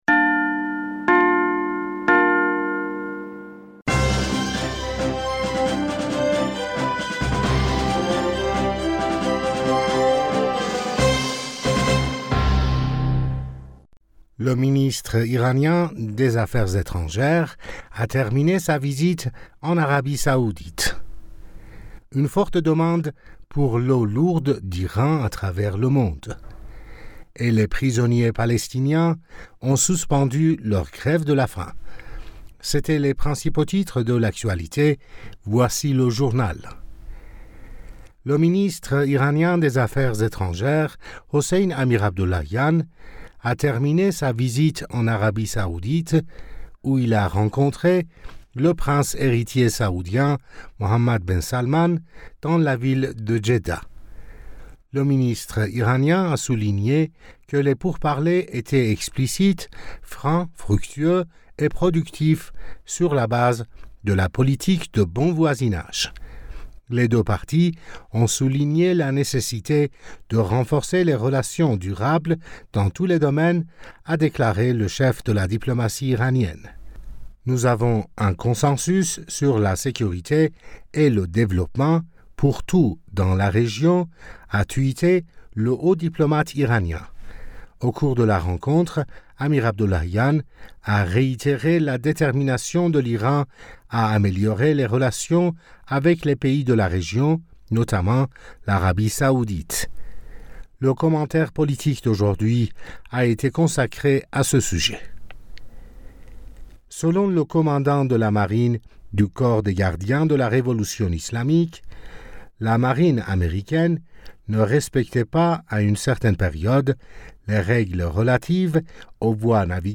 Bulletin d'information du 19 Aout 2023